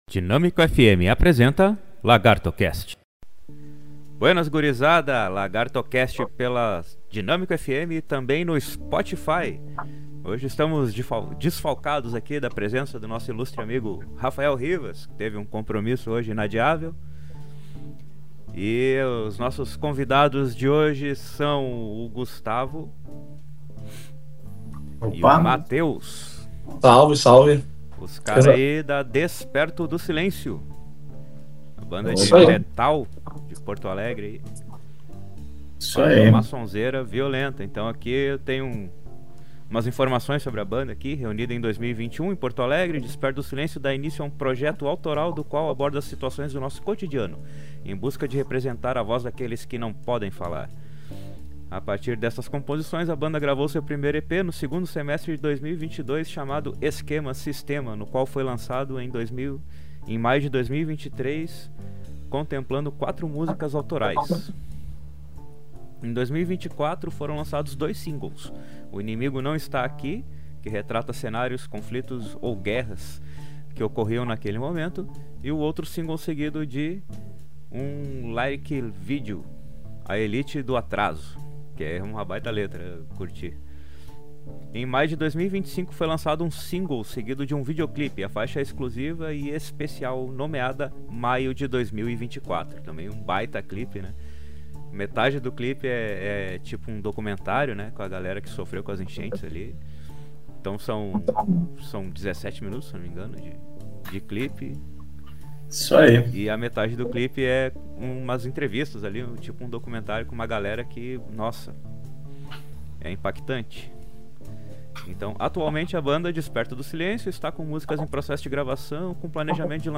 Entrevista com a banda DESPERTO DO SILENCIO